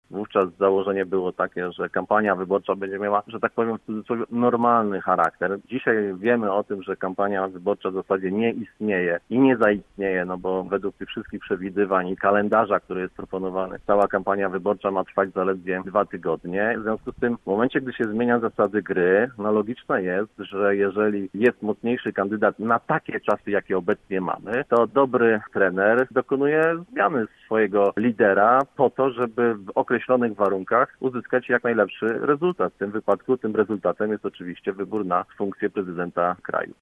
Sławomir Kotylak, miejski radny klubu Koalicji Obywatelskiej wskazywał w Rozmowie Punkt 9, że chodzi o predyspozycje kandydatów w określonych warunkach.